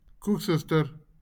A koeksister (/ˈkʊksɪstər/; Afrikaans: [ˈkukˌsəs.tər]